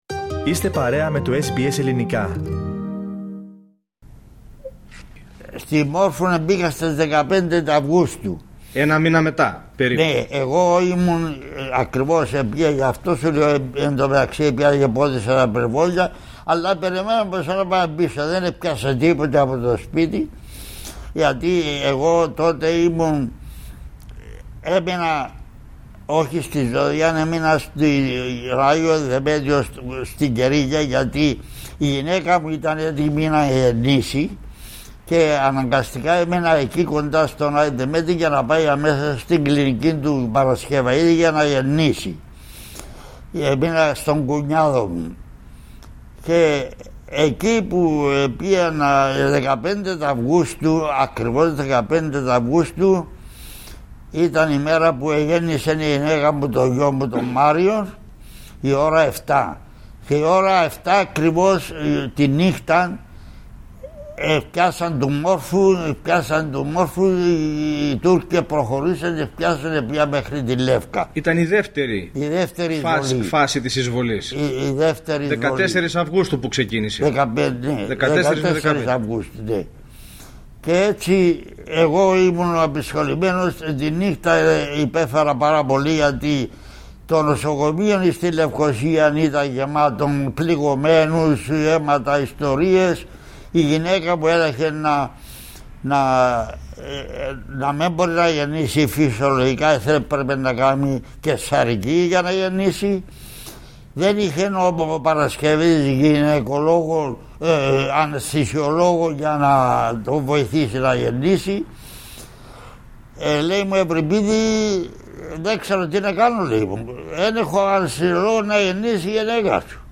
να τον ακούσουμε να την διηγείται ο ίδιος με την γνήσια, πανάρχαια και ελληνικότατη κυπριακή λαλιά